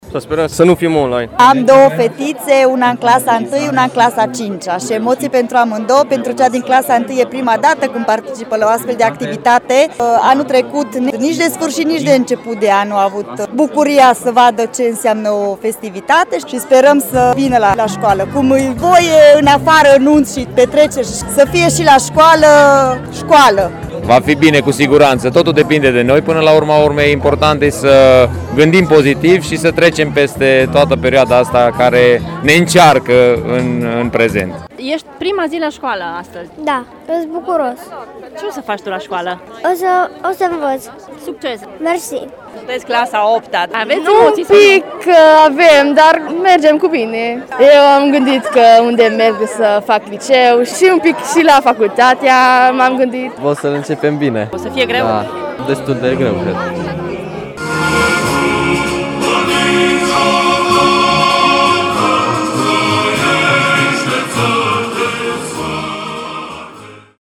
Părinții speră că în această toamnă școala se va desfășura doar față în față: